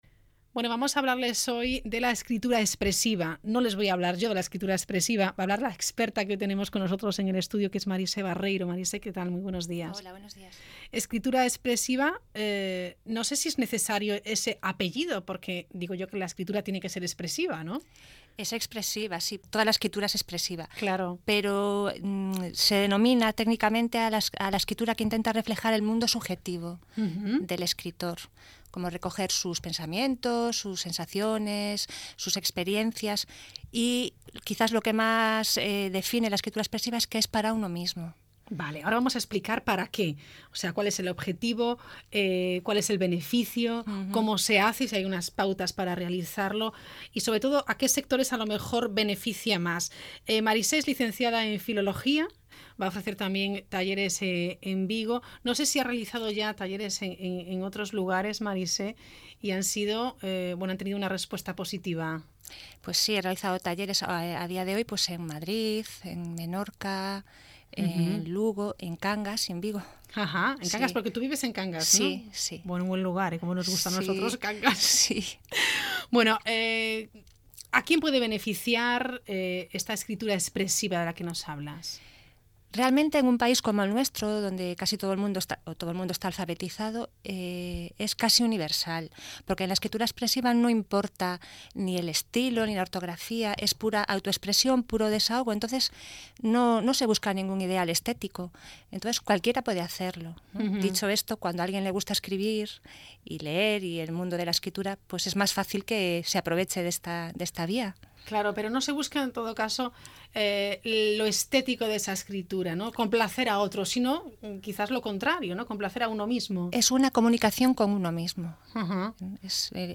Entrevista en «Hoy por hoy»